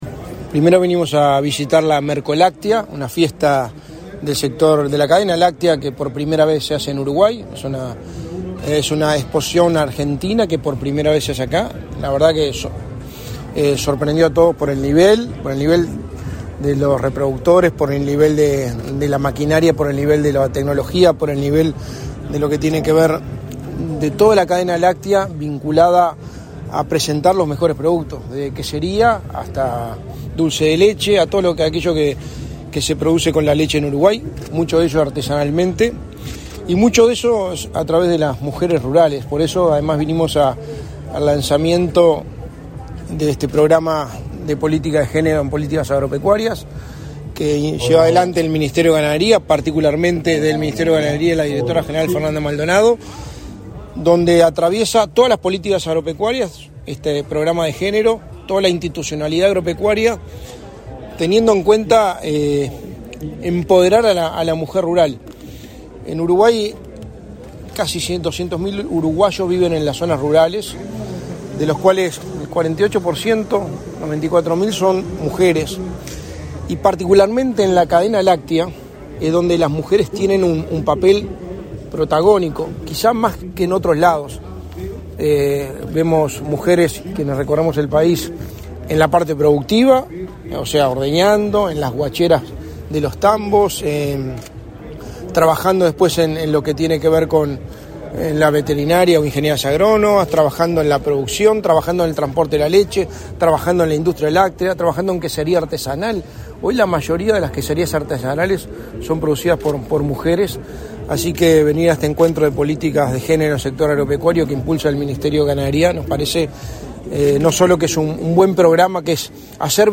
Declaraciones a la prensa del secretario de la Presidencia, Álvaro Delgado
Declaraciones a la prensa del secretario de la Presidencia, Álvaro Delgado 03/06/2023 Compartir Facebook X Copiar enlace WhatsApp LinkedIn Este 3 de junio, el secretario de la Presidencia de la República, Álvaro Delgado, visitó la exposición Mercoláctea, que se realiza en la Rural del Prado. En la oportunidad, el jerarca realizó declaraciones a la prensa.